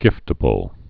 (gĭftə-bəl)